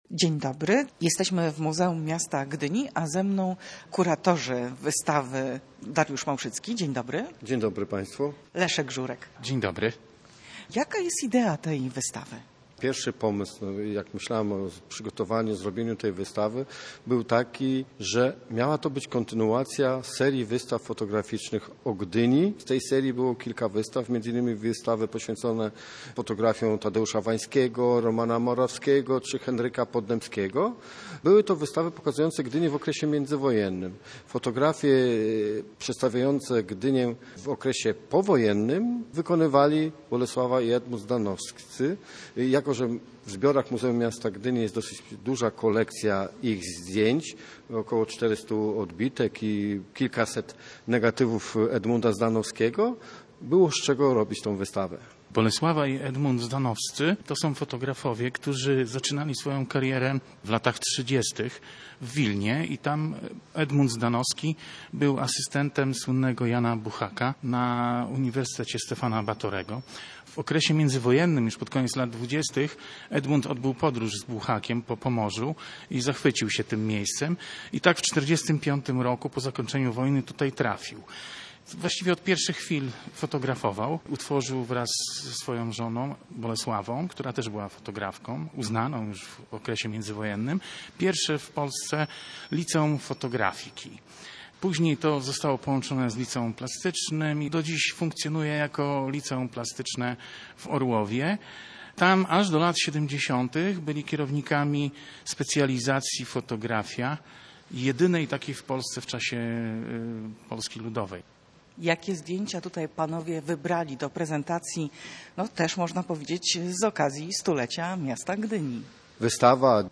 Wywiad_kultura_Swiatlo_Gdyni.mp3